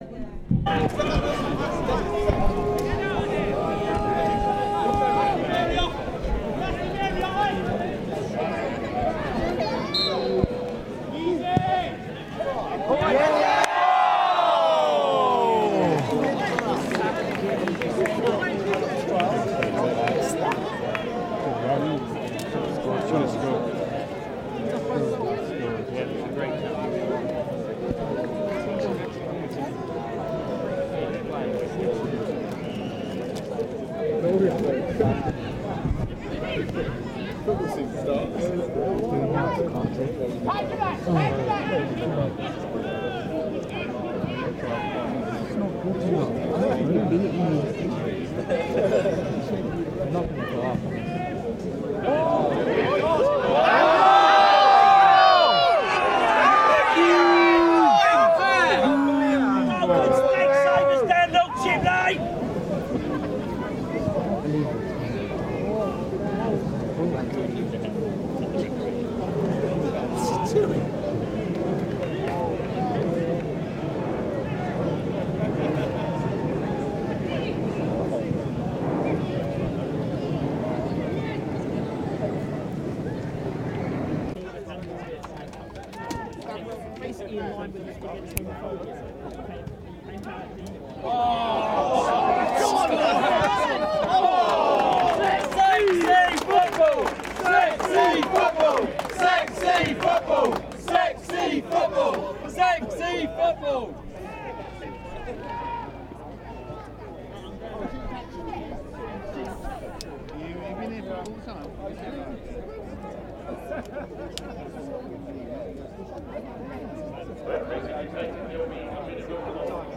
Noise and the Megacity is a dive into an archive of collected noises from ten megacities across the globe. Interviews and ruminations will be mixed in with these sounds in search of a better understanding of a fundamental question: "what is noise?" This month on the program, we listen to a grab bag of noise and music from various megacities.